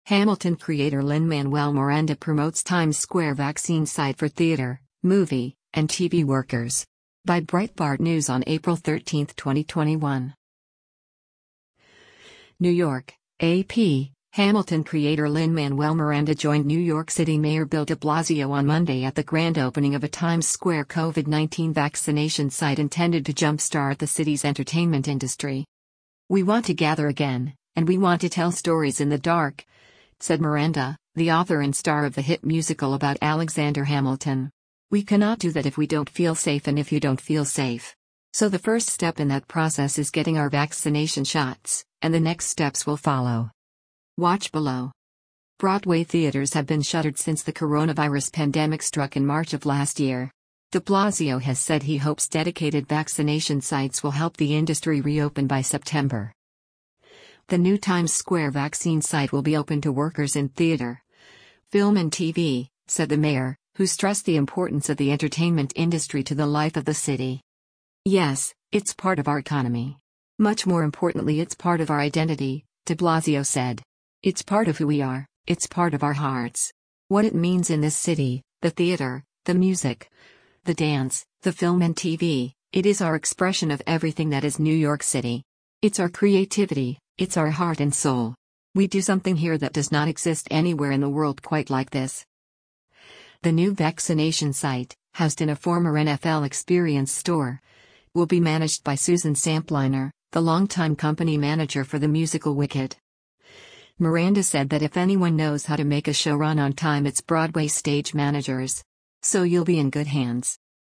Actor Lin-Manuel Miranda delivers his remarks in Times Square after he toured the grand op
NEW YORK (AP) — “Hamilton” creator Lin-Manuel Miranda joined New York City Mayor Bill de Blasio on Monday at the grand opening of a Times Square COVID-19 vaccination site intended to jump-start the city’s entertainment industry.